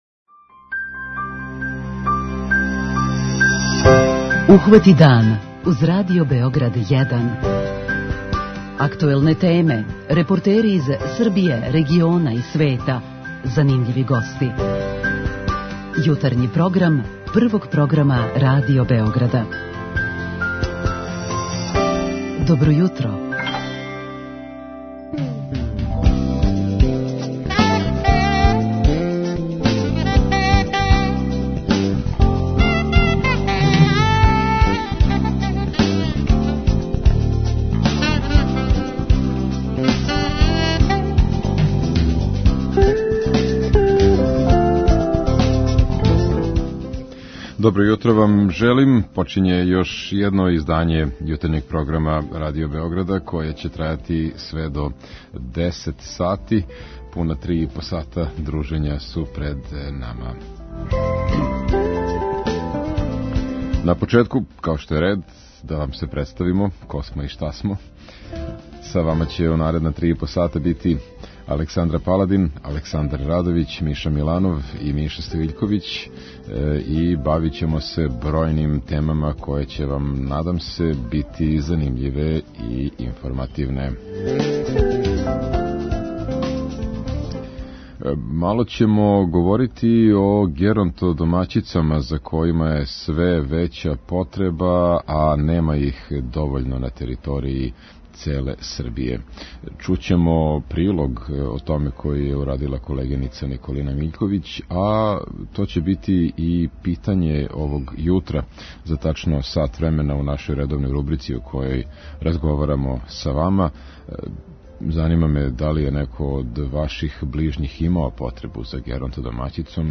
Чућемо и прилог о једној лепој и хуманој акцији која се спроводи у Врању баш у вези с помагањем најстаријим суграђанима. преузми : 37.70 MB Ухвати дан Autor: Група аутора Јутарњи програм Радио Београда 1!